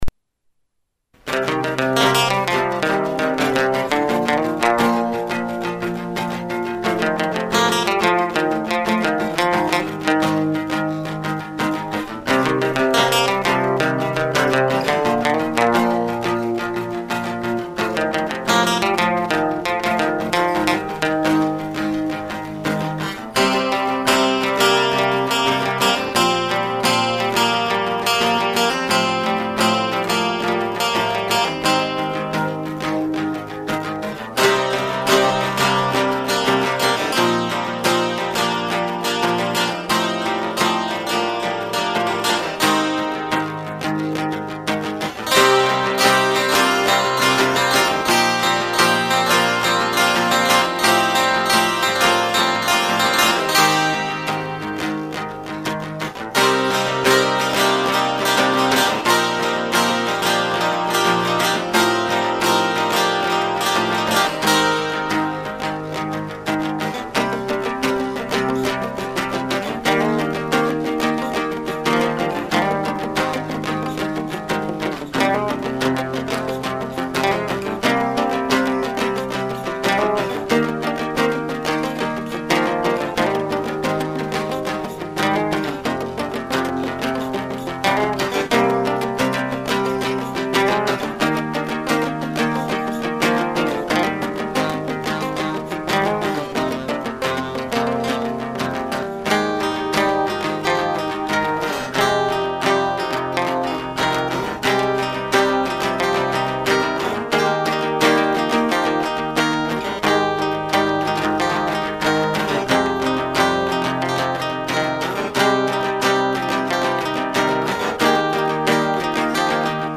Sem letra.